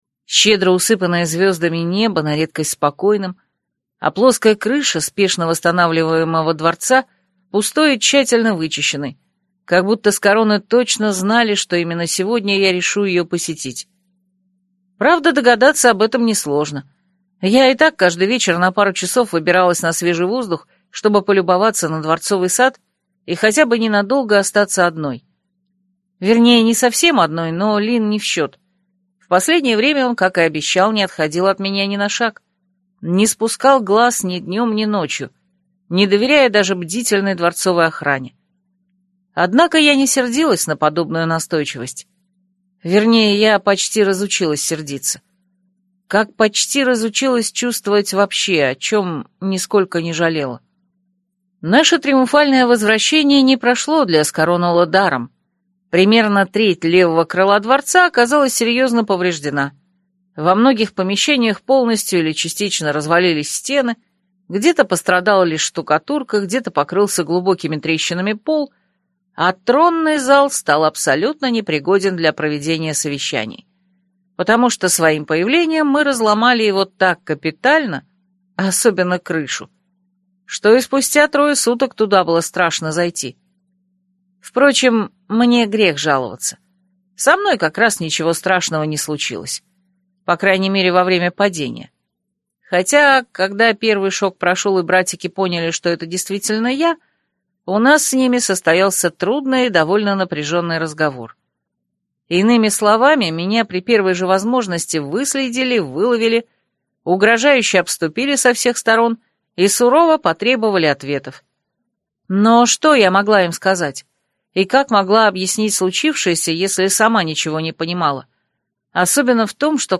Прослушать фрагмент аудиокниги Последняя партия Александра Лисина Произведений: 42 Скачать бесплатно книгу Скачать в MP3 Вы скачиваете фрагмент книги, предоставленный издательством